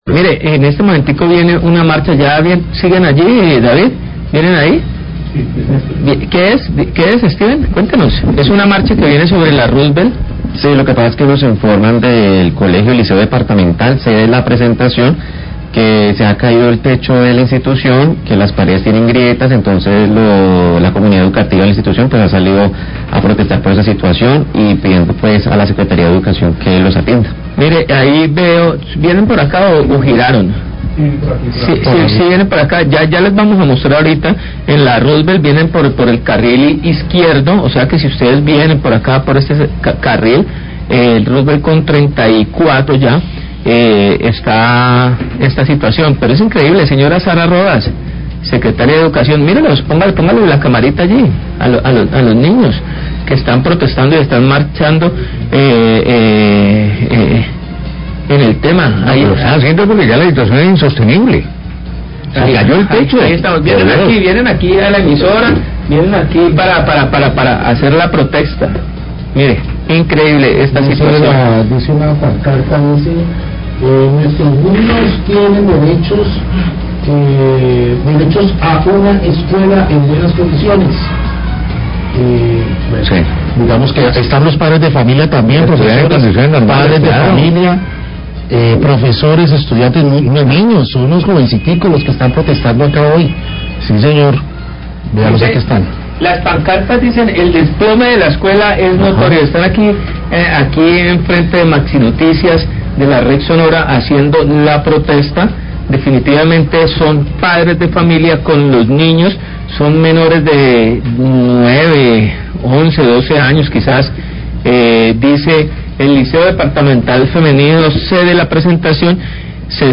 Radio
Se realiza marcha de protesta de la comunidad educativa y los niños del Liceo Dptal Femenino pór el deterioro y desplome progresivo de sus instalaciones representando un riesgo para los estudiantes. Periodistas hacen un llamado a la secretaria de Educación, Sara Rodas y al alcalde Eder.